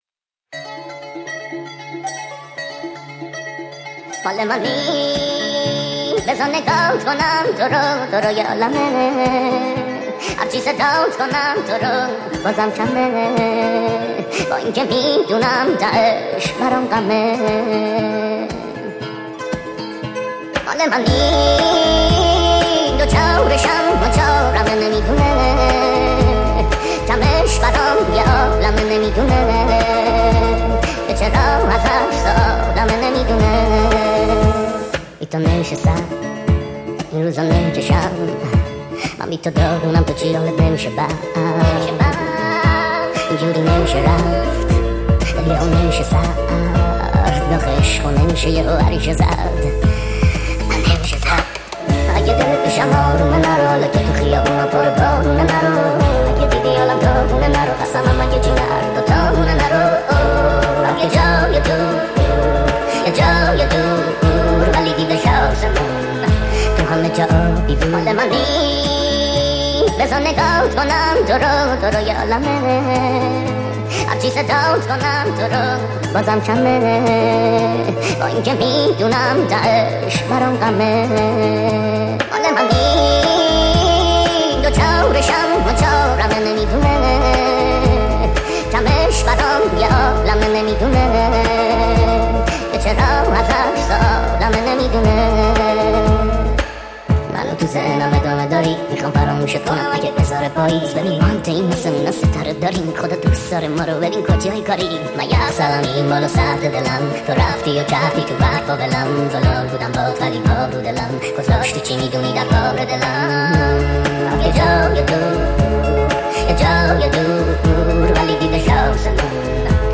با صدای بچه